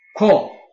臺灣客語拼音學習網-客語聽讀拼-饒平腔-入聲韻
拼音查詢：【饒平腔】kug ~請點選不同聲調拼音聽聽看!(例字漢字部分屬參考性質)